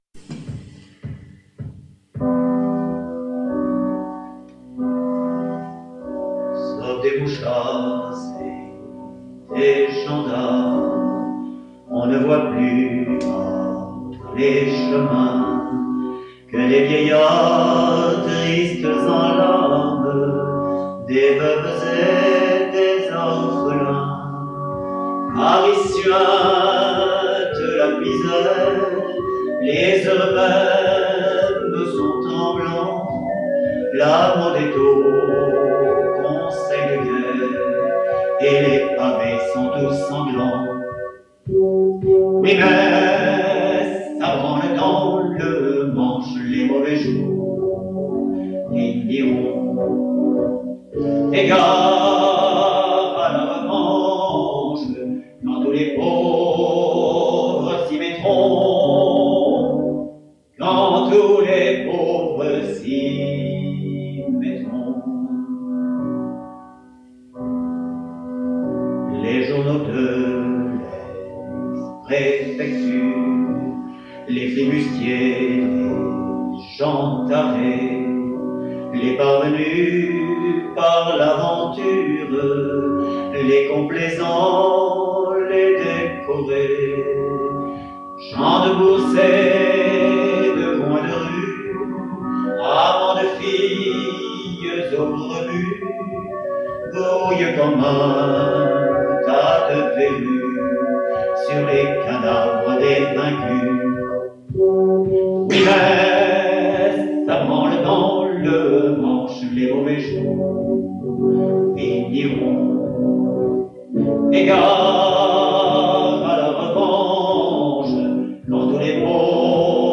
La Semaine sanglante. (Enregistr�e en public.)